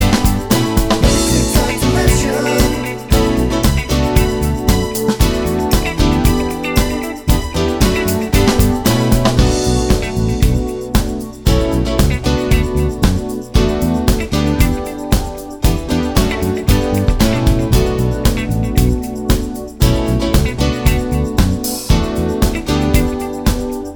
Live In Chile 2009 Pop (1980s) 5:10 Buy £1.50